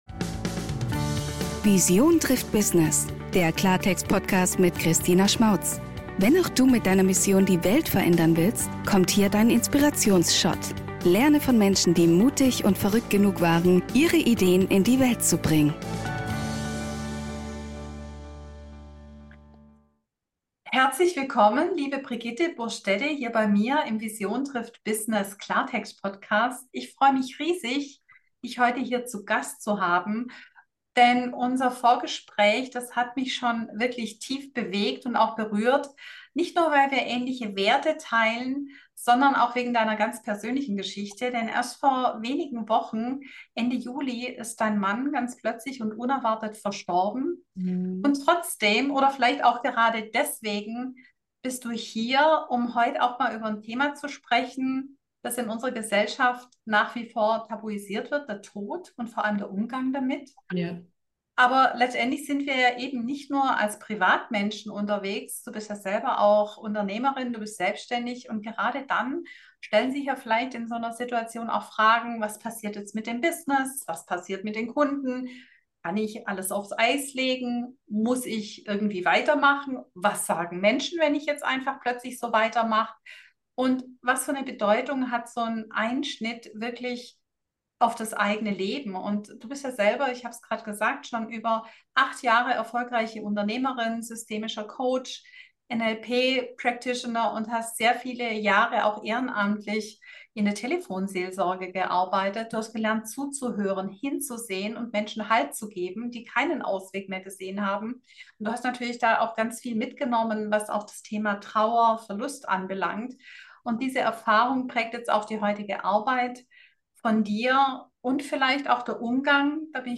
In diesem tiefgründigen Gespräch geht es um Menschlichkeit, Bewusstsein und Verbundenheit in einer Welt, die oft nur Stärke feiert, aber Trauer und Verletzlichkeit meidet.